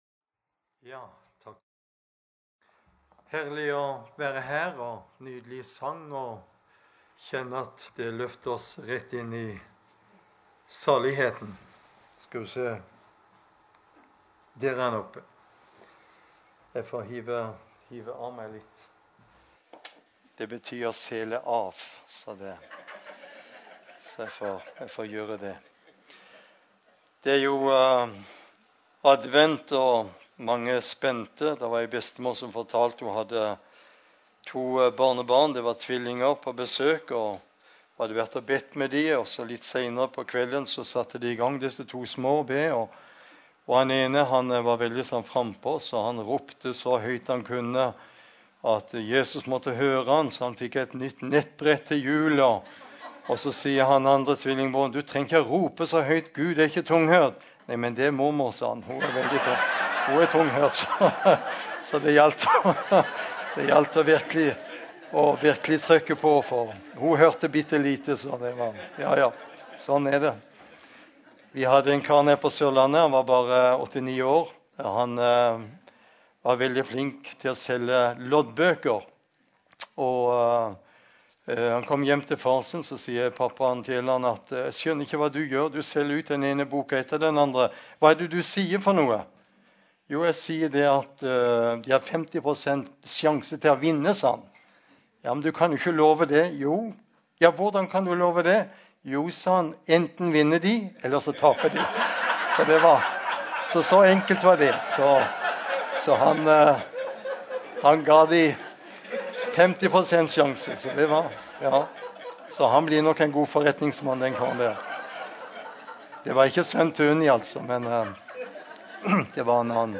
Søndagsgudstjeneste 2019
Preken-GT-11.mp3